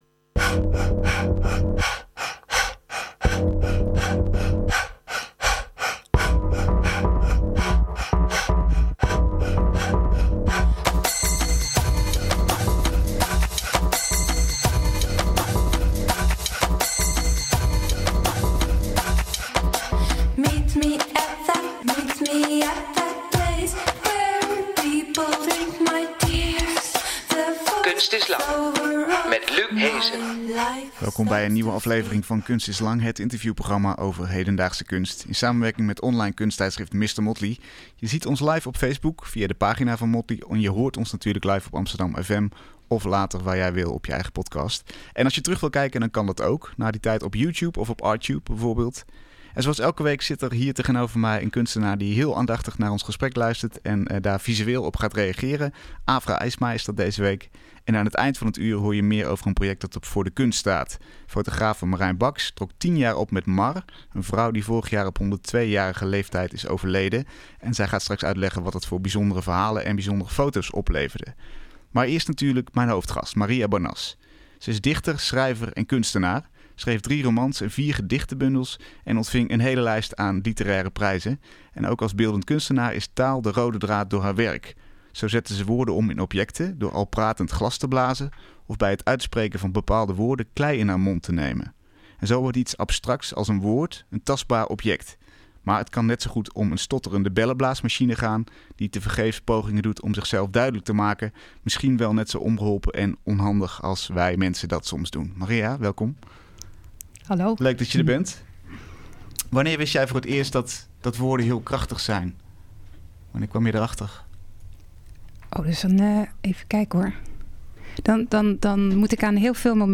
Een gesprek over de scheppende kracht van woorden, en het speelveld tussen poging en mislukking - waar de meeste kunst geboren wordt.